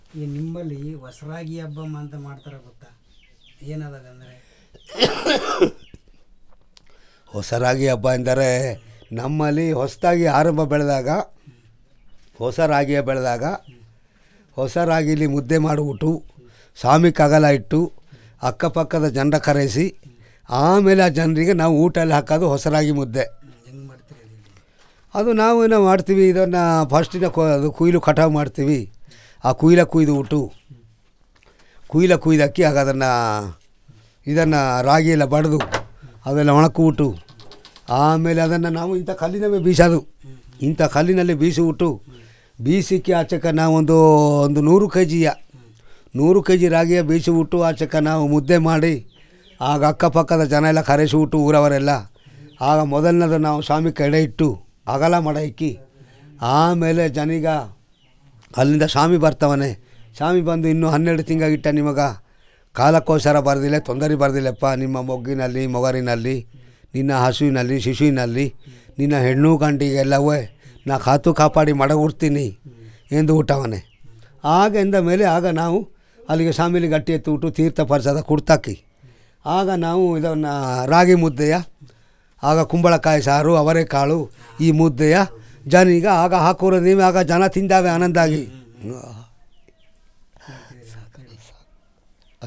Traditional story about Hosaraagi habba